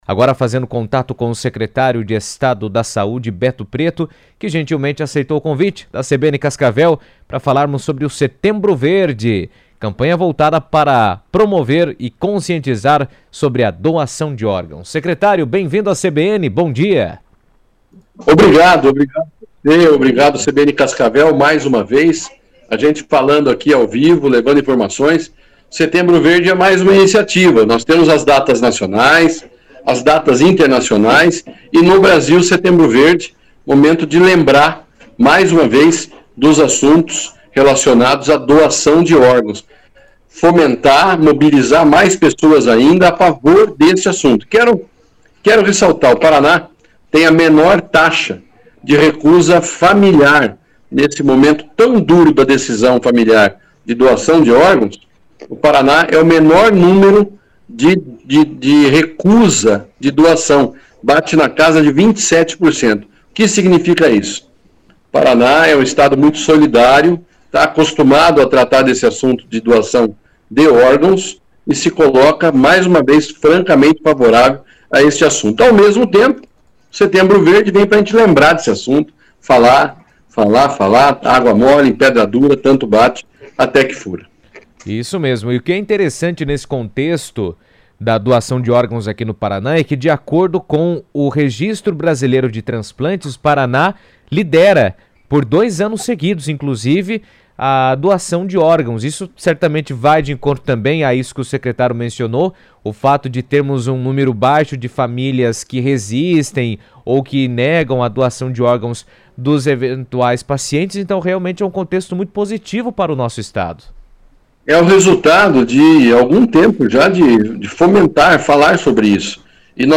O Setembro Verde é uma campanha dedicada a conscientizar a população sobre a importância da doação de órgãos e o impacto que esse gesto pode ter na vida de milhares de pessoas que aguardam por um transplante. Em entrevista à CBN, o Secretário de Saúde do Paraná, Beto Preto, destacou os desafios e avanços do estado nessa área, reforçando o papel da sociedade na ampliação das doações.